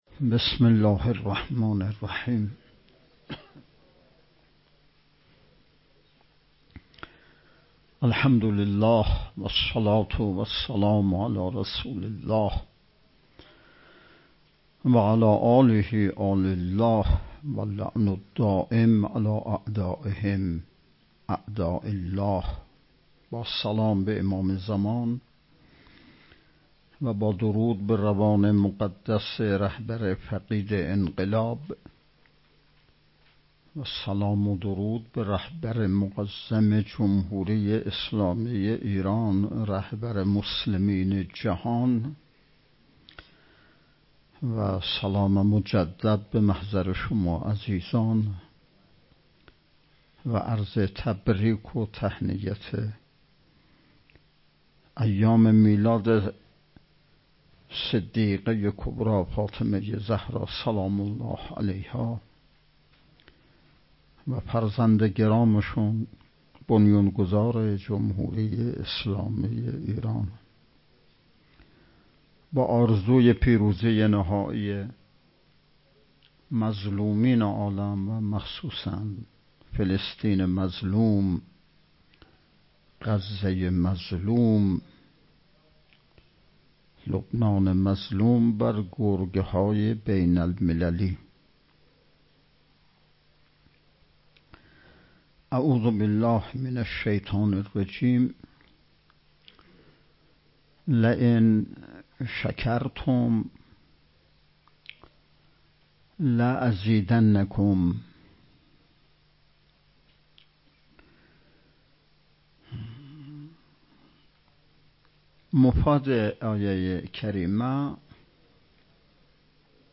دوازدهمین نشست ارکان شبکه تربیتی صالحین بسیج با موضوع تربیت جوان مؤمن انقلابی پای کار، صبح امروز ( ۶ دی) با حضور و سخنرانی نماینده ولی فقیه در استان، برگزار شد.